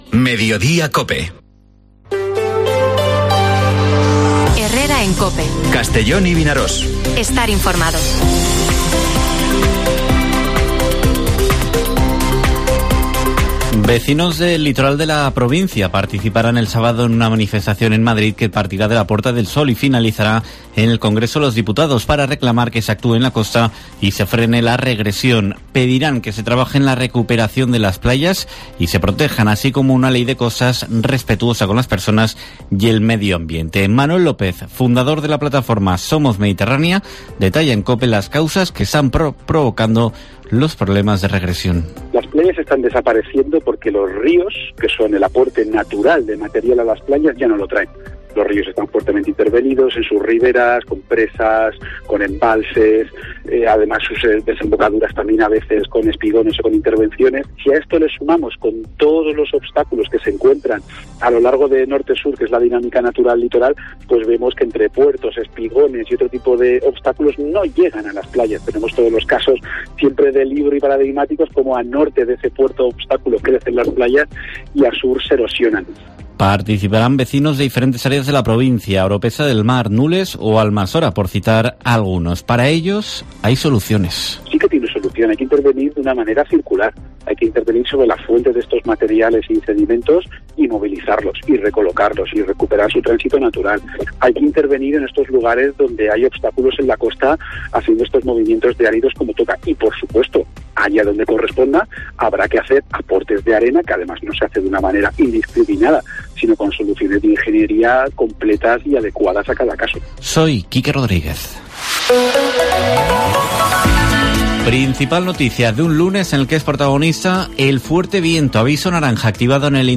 Informativo Mediodía COPE en la provincia de Castellón (16/01/2023)